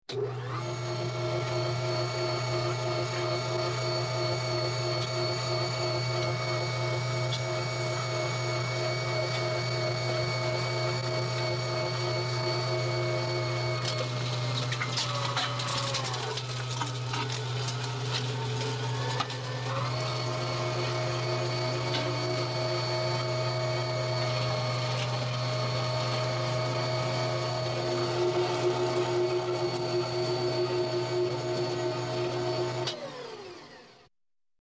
Звуки мясорубки
Профессиональная мясорубка для кухни